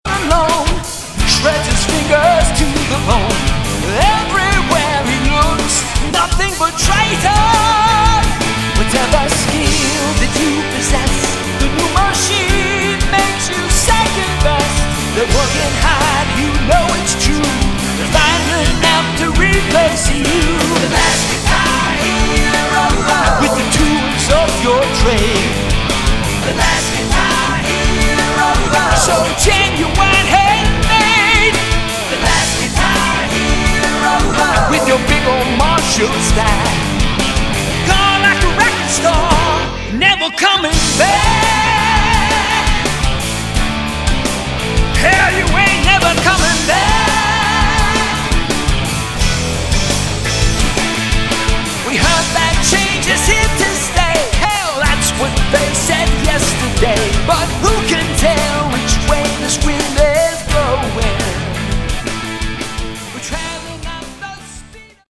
Category: AOR
lead and backing vocals, keyboards, synth bass
guitar solo